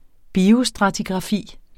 Udtale [ ˈbiːo- ]